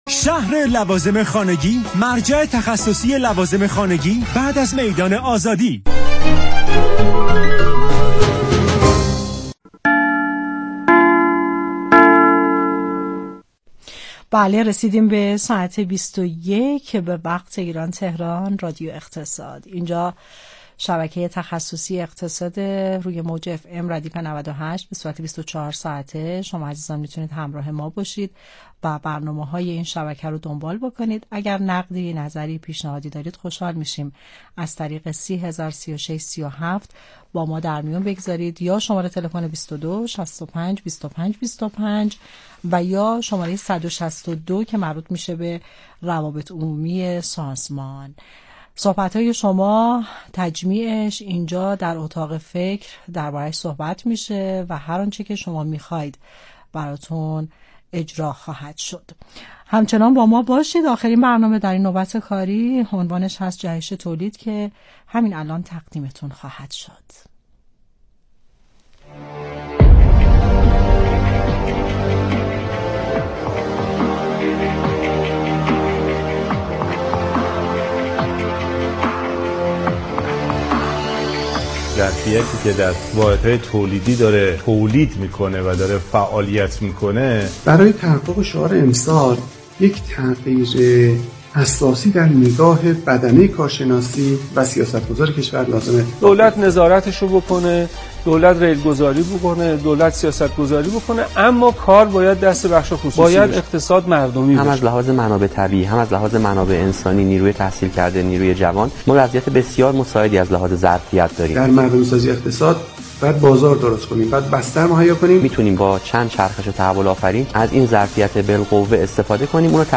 مصاحبه رادیویی انجمن پلی‌یورتان ایران با رادیو اقتصاد